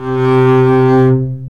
Index of /90_sSampleCDs/Roland L-CD702/VOL-1/STR_Cb Bowed/STR_Cb1 mf vb